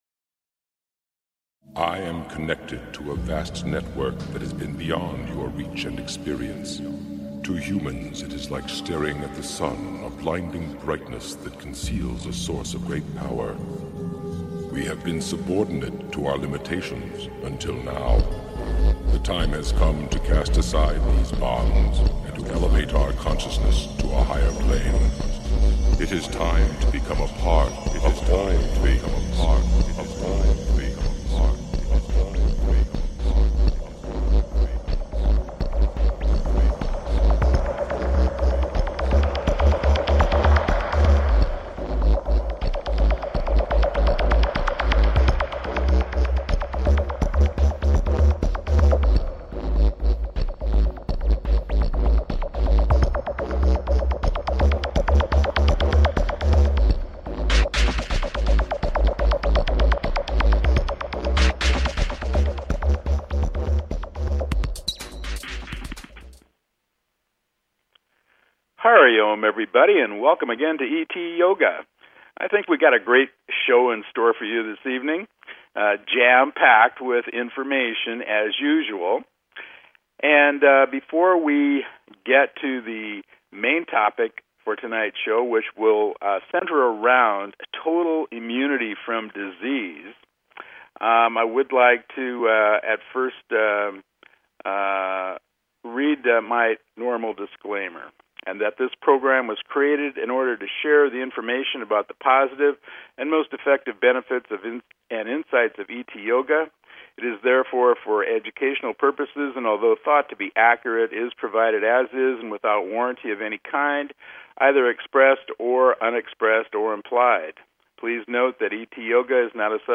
It also included an activation of your template for Perfect Health and Well Being. It also contained the Hari Om mantra meditation and XYZ Extraterrestrial meditation.